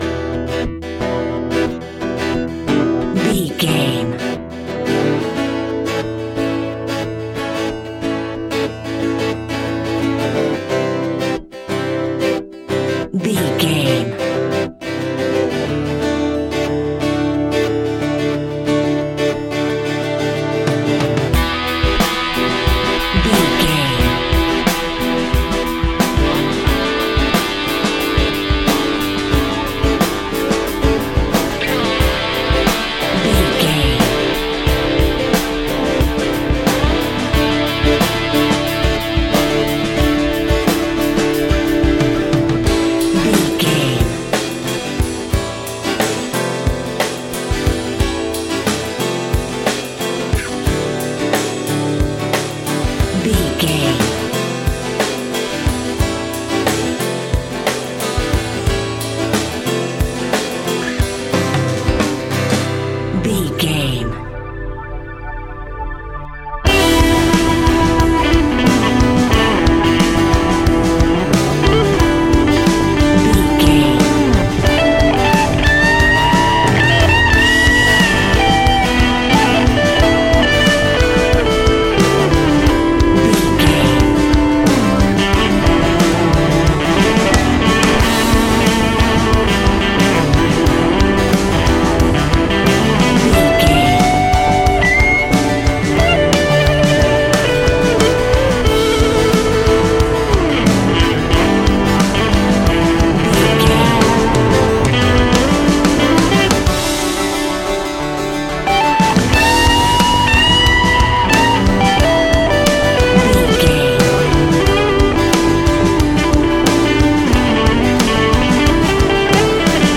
Aeolian/Minor
romantic
sweet
happy
acoustic guitar
bass guitar
drums